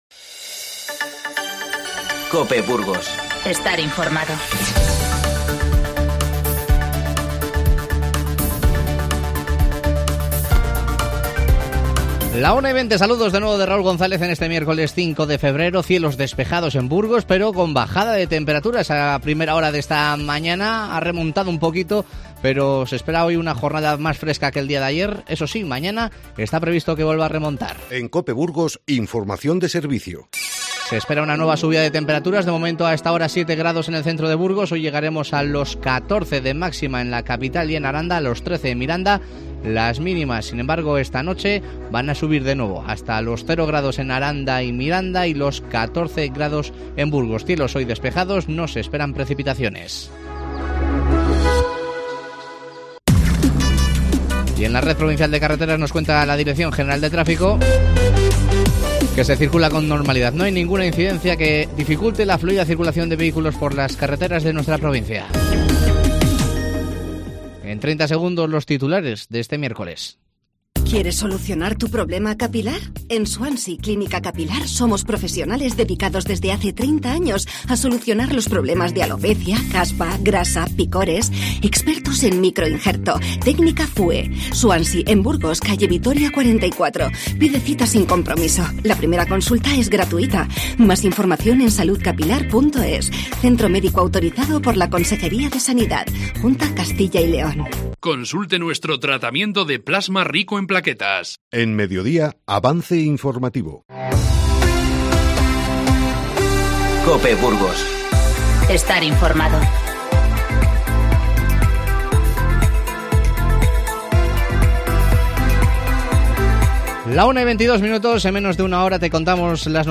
Redacción digital Madrid - Publicado el 05 feb 2020, 13:40 - Actualizado 16 mar 2023, 17:29 1 min lectura Descargar Facebook Twitter Whatsapp Telegram Enviar por email Copiar enlace Te avanzamos las principales noticias del día y charlamos con representantes de la plataforma que se ha creado en Burgos contra la despoblación que asola nuestra provincia.